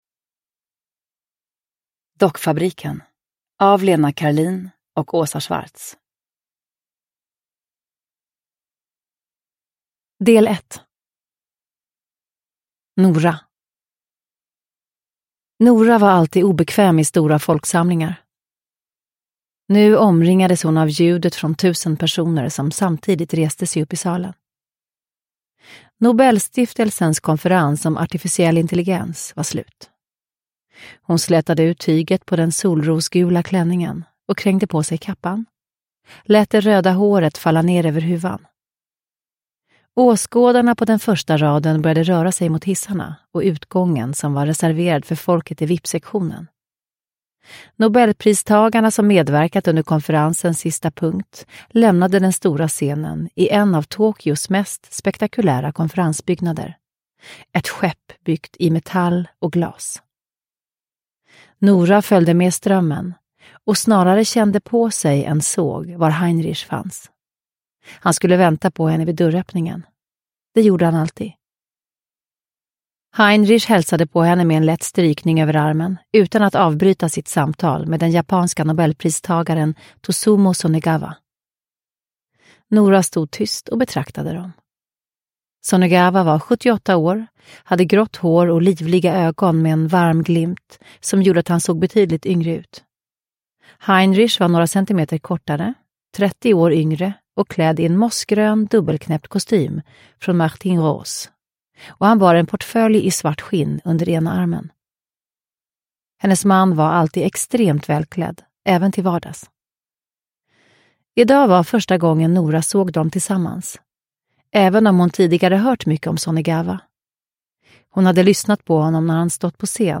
Dockfabriken – Ljudbok – Laddas ner
Uppläsare: Eva Röse